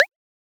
edm-perc-41.wav